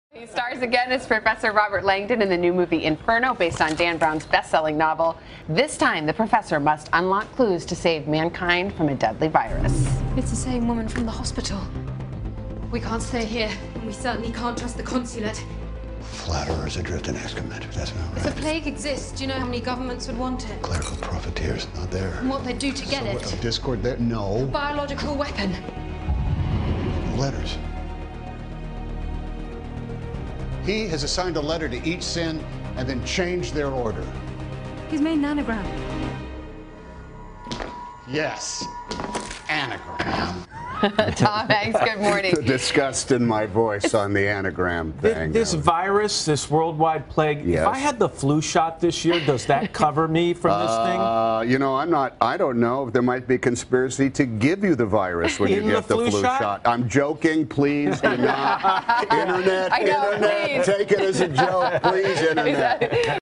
Tom Hanks interview (2016) - FLU SHOT CONSPIRACY